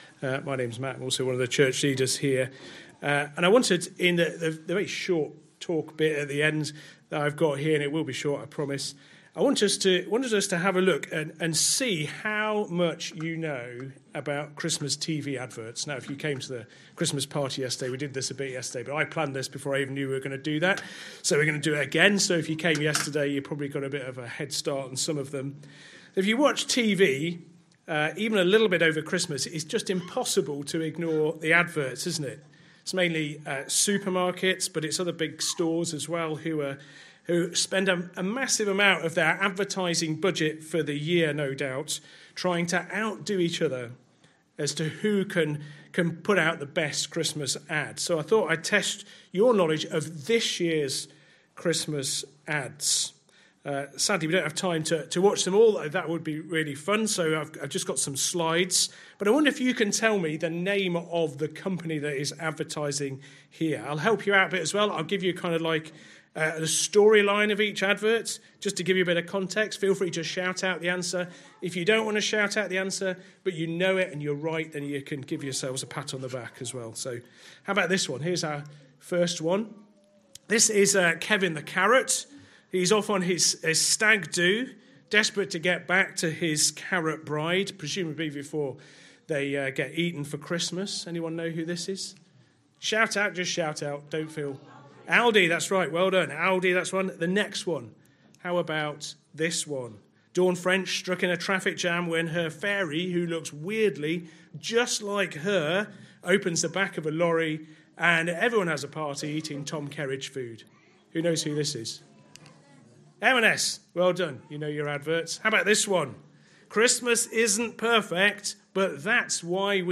Christ Church Sermon Archive